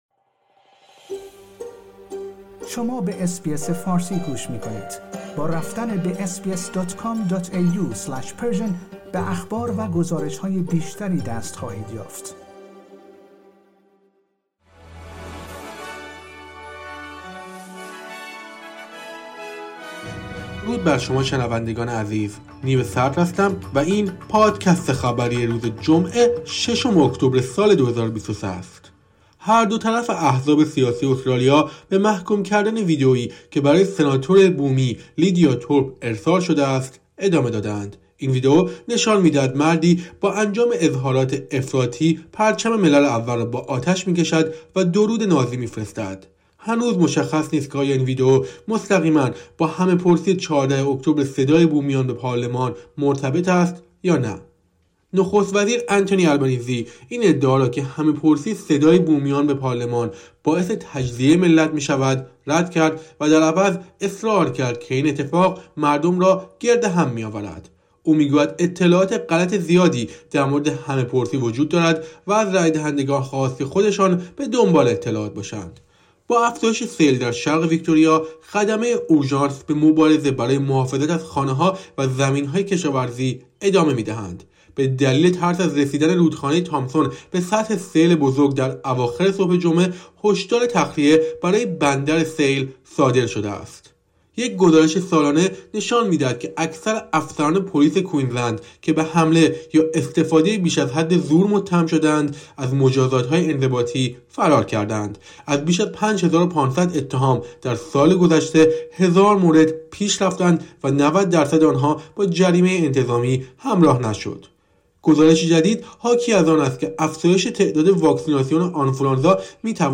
در این پادکست خبری مهمترین اخبار استرالیا و جهان در روز جمعه ۶ اکتبر، ۲۰۲۳ ارائه شده است.